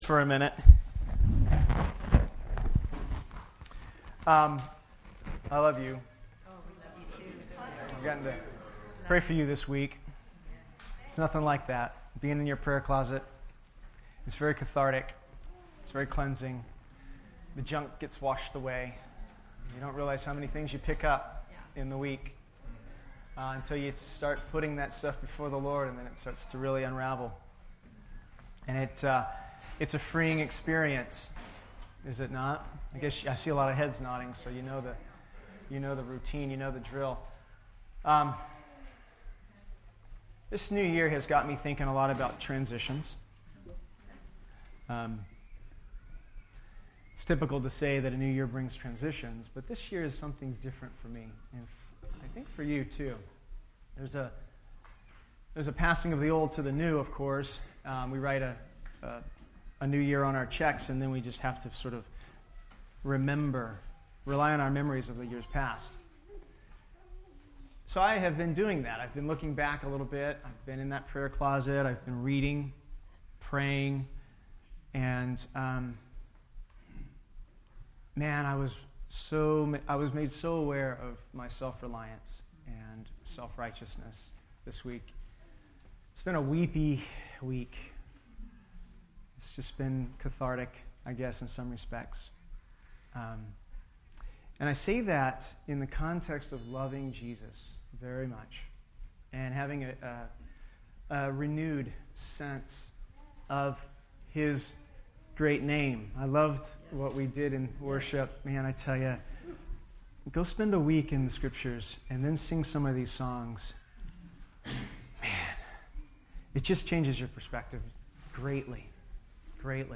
FWC Sermons Service Type: Sunday Morning